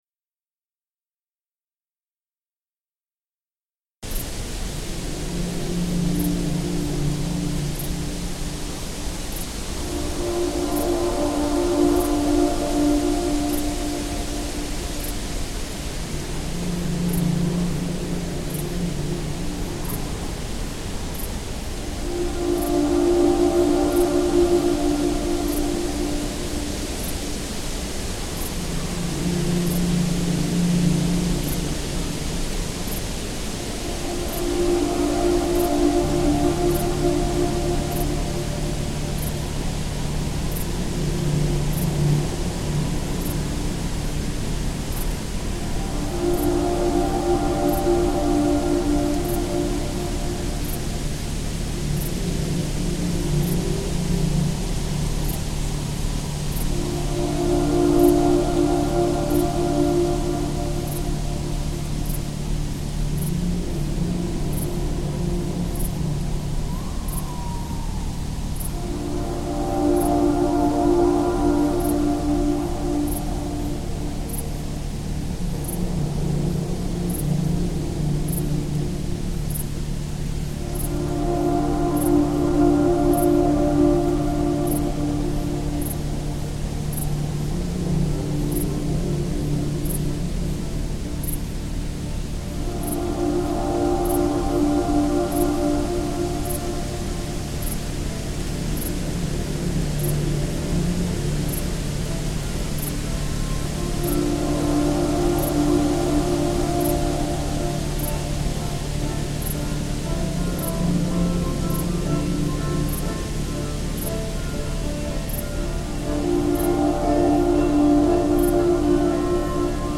I decided not to edit or rearrange the field recording, but to provide an accompaniment to it. I wanted to marry sounds that are digital with the organic sounds of nature - so even though the melodies were written on guitar I chose to use far less natural sounding synth plugins when it came to recording.